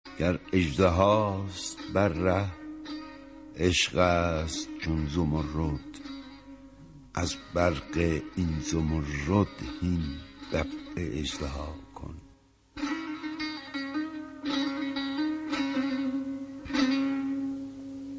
دکلمه‌ای از این غزل مولوی با صدای شاملوی بزرگ:
Shamlu-Nazeri-Ro-Sar-Benah-Trimmed.mp3